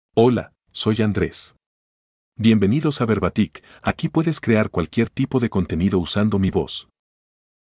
Andres — Male Spanish (Guatemala) AI Voice | TTS, Voice Cloning & Video | Verbatik AI
Andres is a male AI voice for Spanish (Guatemala).
Voice sample
Listen to Andres's male Spanish voice.
Andres delivers clear pronunciation with authentic Guatemala Spanish intonation, making your content sound professionally produced.